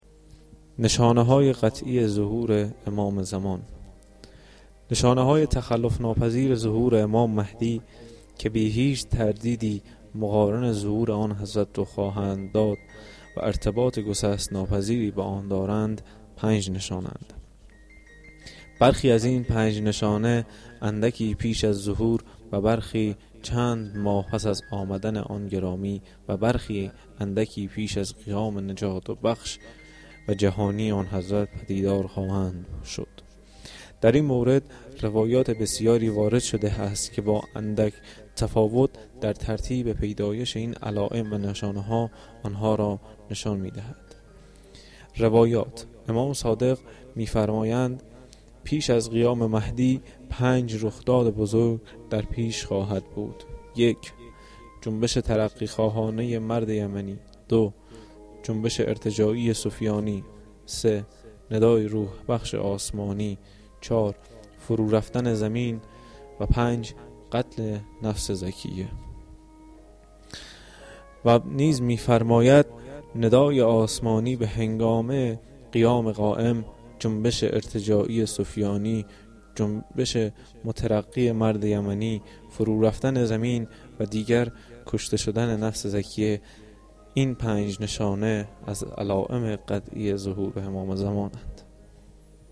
نمایش رادیویی و کتاب گویا